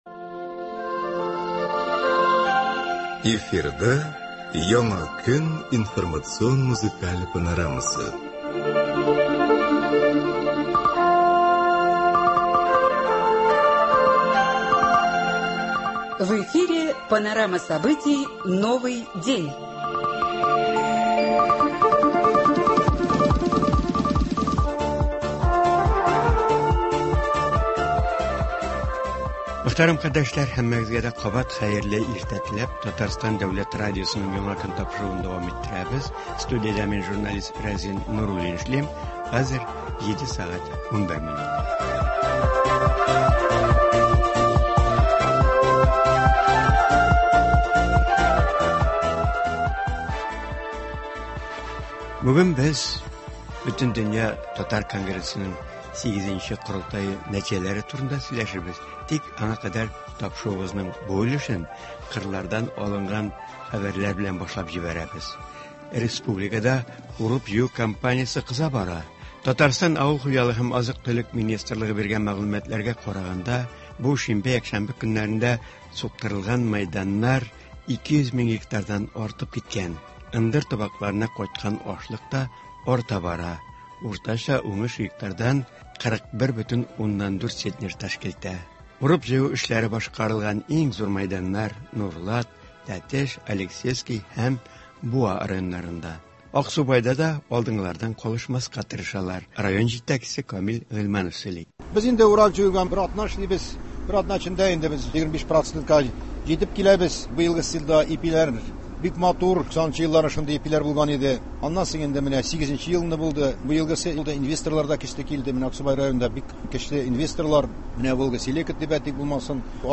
Туры эфир (08.08.22)